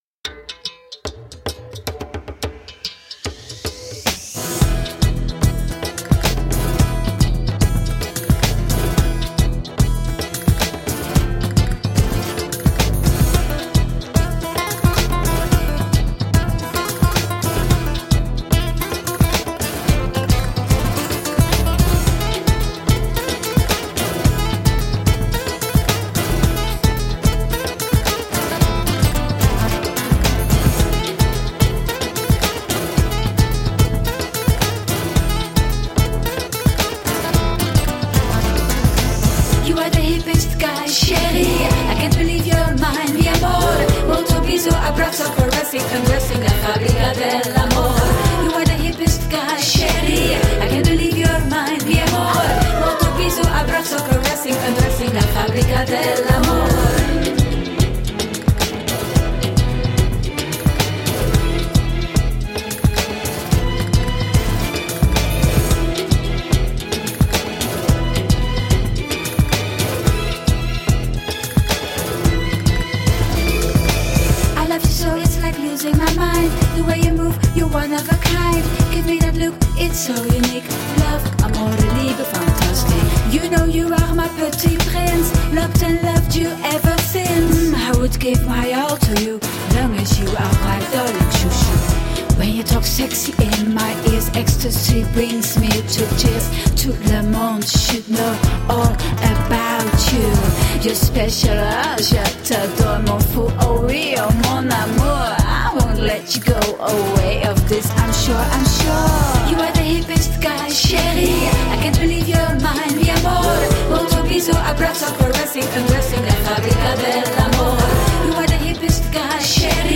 Positive vibe electro pop.
Tagged as: Electro Rock, Other